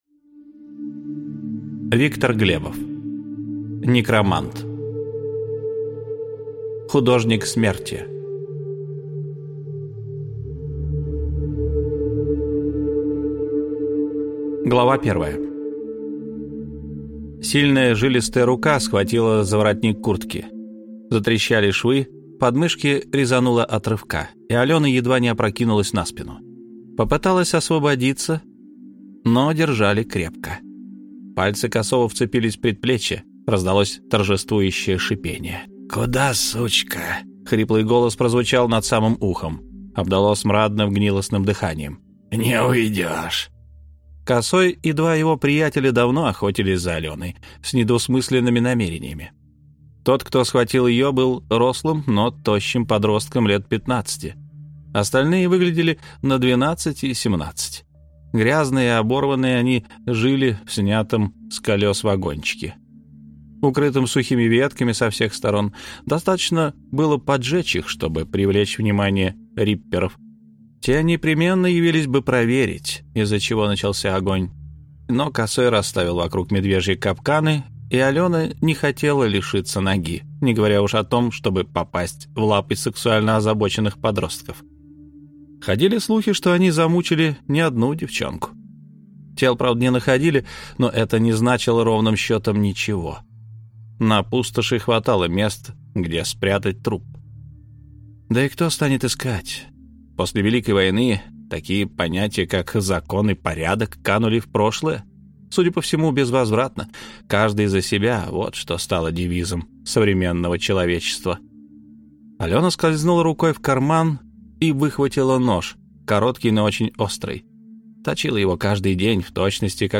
Аудиокнига Некромант: Художник смерти | Библиотека аудиокниг